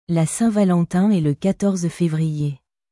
La Saint-Valentin est le quatorze févrierラ サン ヴァラォンタン エ ル カトルズ フェヴリエ